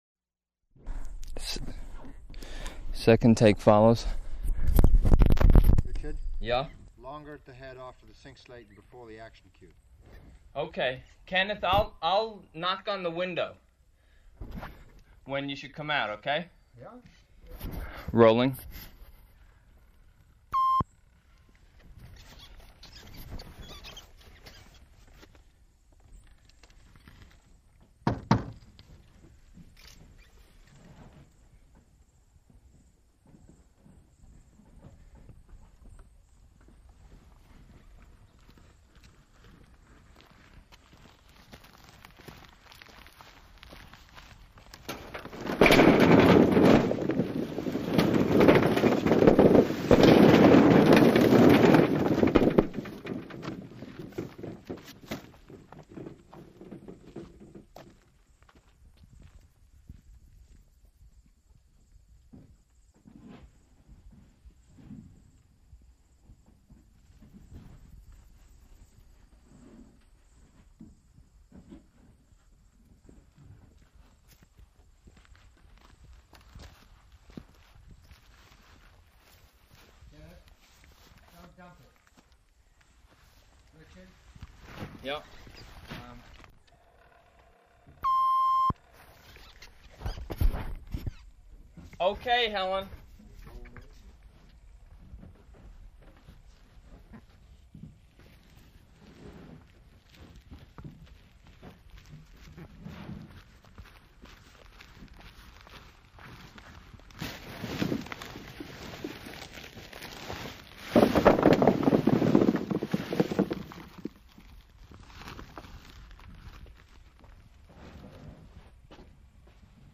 Format 1 sound tape reel (Scotch 3M 208 polyester) : analog ; 7 1/2 ips, full track, mono.
Chelsea (inhabited place) Vermont (state)